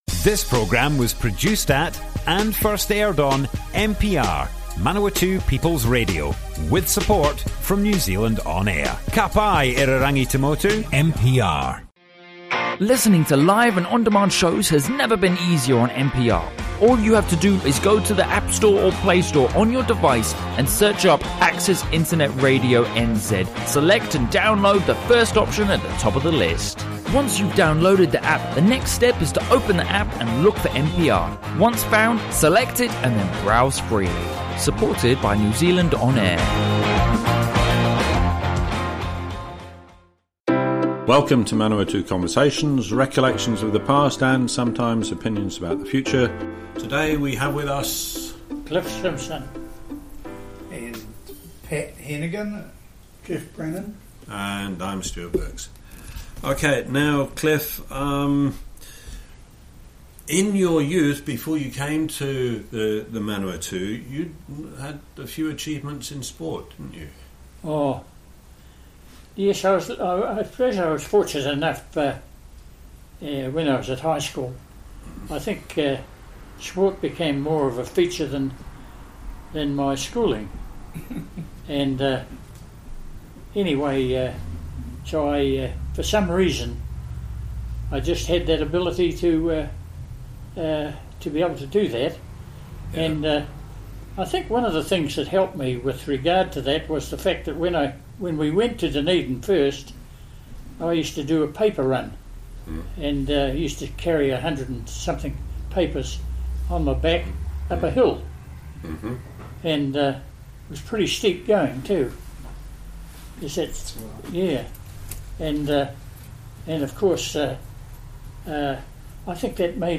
Manawatū Conversations More Info → Description Broadcast on Access Manawatū on 9 April, 2019.
oral history